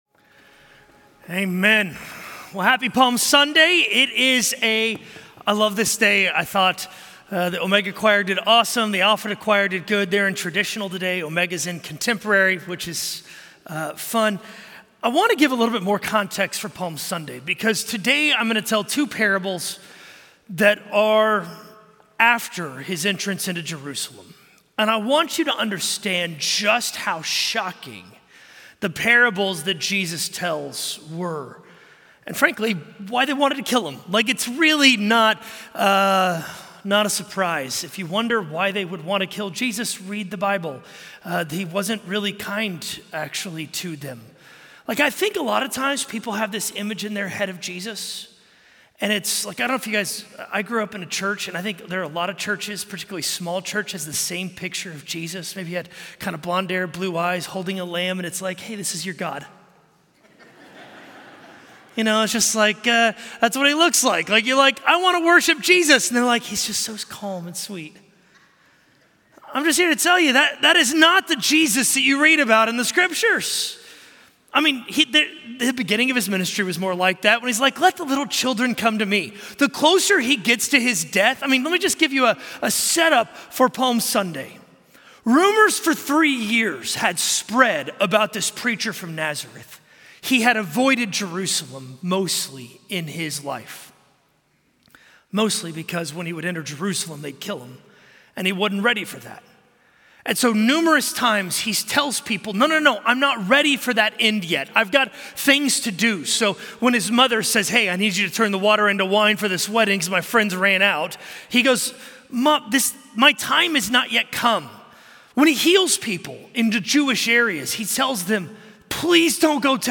A message from the series "Jesus Parables."